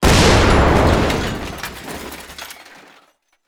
metal3.wav